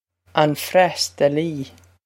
an freastalaí on fras-tahl-y
Pronunciation for how to say
on fras-tahl-y